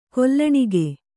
♪ kollaṇige